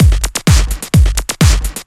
Electrohouse Loop 128 BPM (5).wav